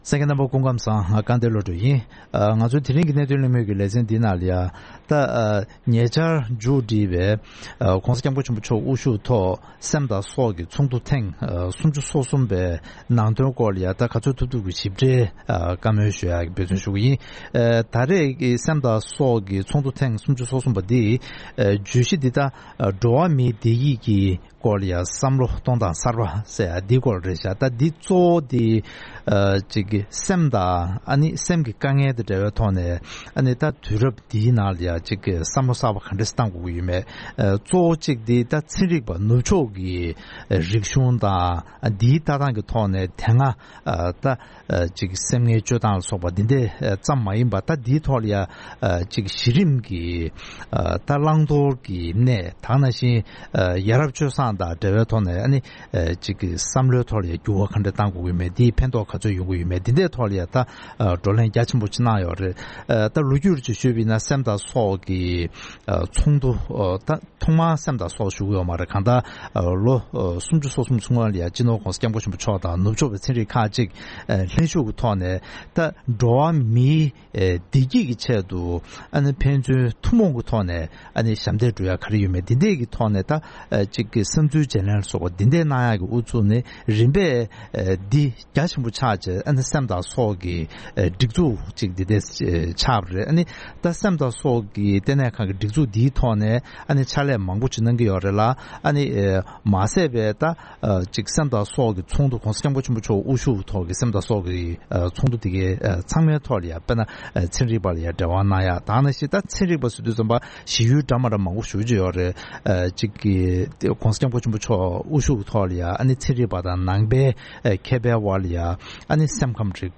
ཉེ་ཆར་སྐོང་ཚོགས་གནང་བའི་སེམས་དང་སྲོག་གི་བགྲོ་གླེང་ཚོགས་འདུ་དེ་བརྒྱུད་ནང་པ་དང་ཚན་རིག་པ་ཕྱོགས་སོ་སོར་བློ་སྐྱེ་དང་མཁེ་ཕན་ཇི་བྱུང་སོགས་ཀྱི་སྐོར་གླེང་མོལ།